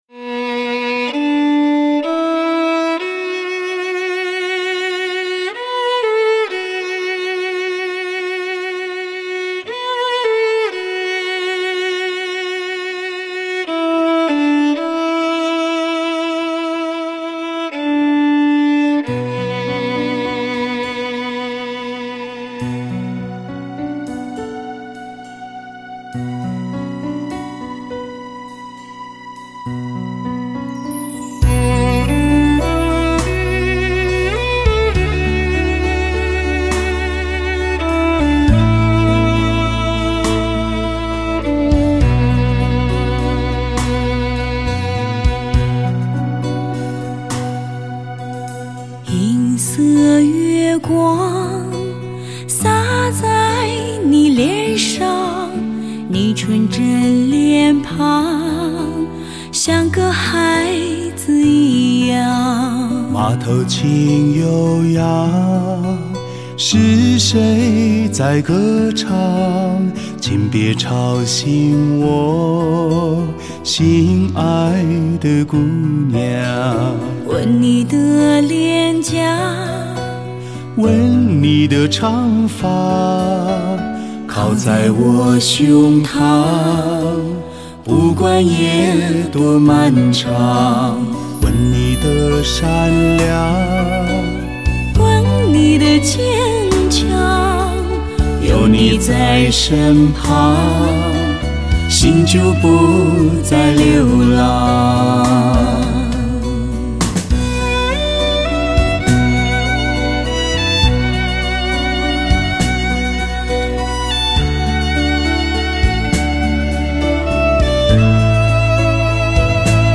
洁静纯美的圣音本应源自天上来